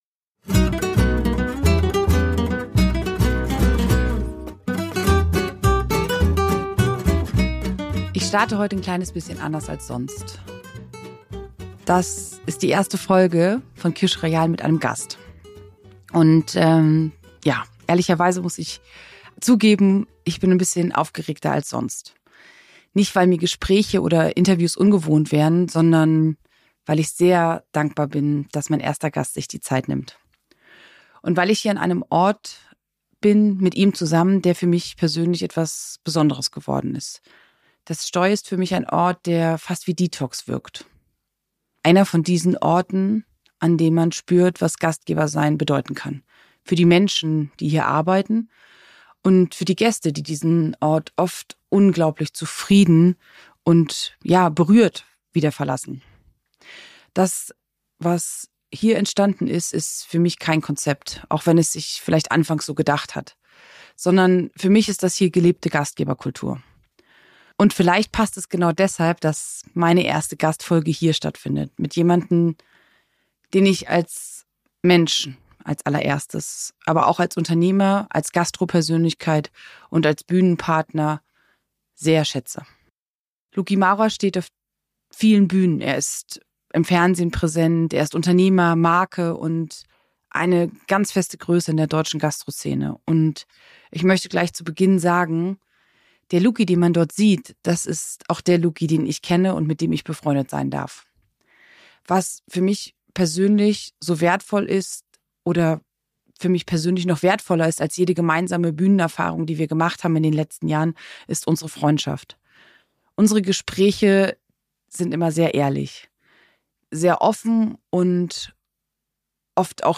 Aufgenommen haben wir die Folge im STOI, einem Ort, der für gelebte Gastgeberkultur steht. Wir sprechen über eine Branche, die fordert. Über Verantwortung, Entwicklung und die Frage, warum Menschen bleiben – obwohl sie es sich leichter machen könnten.